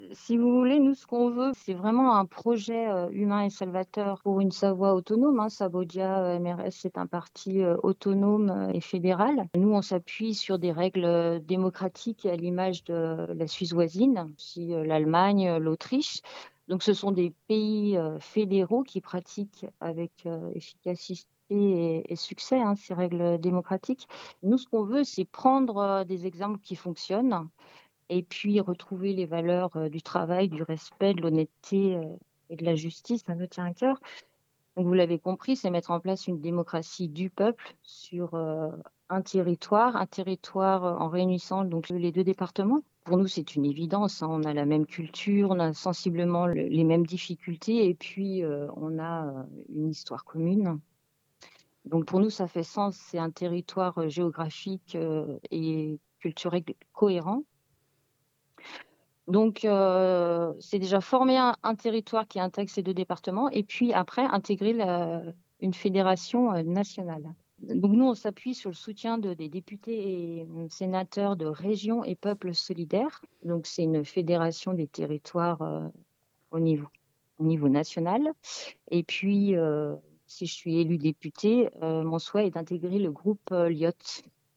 Voic les interviews des 5 candidats de cette 3eme circonscription de Haute-Savoie (par ordre du tirage officiel de la Préfecture) et tous les candidats en Haute-Savoie et en Savoie.